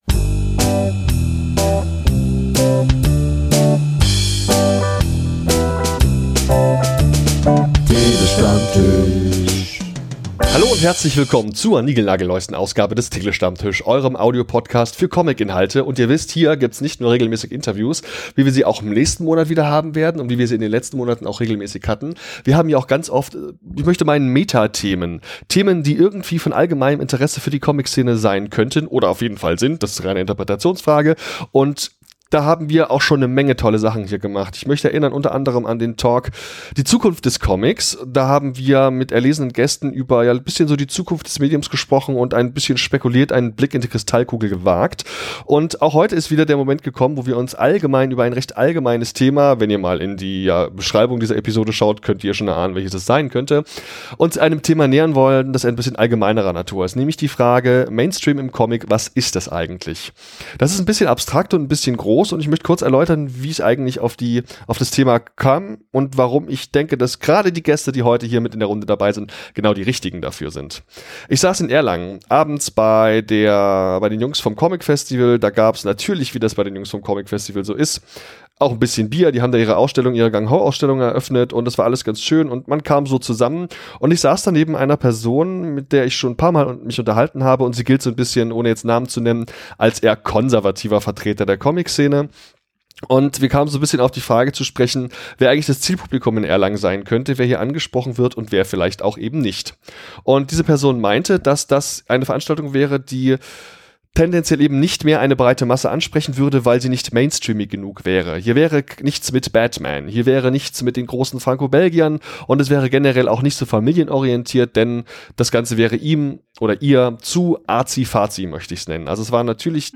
~ Der Tele-Stammtisch - Comictalks & Interviews Podcast